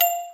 Sound (Mallet).wav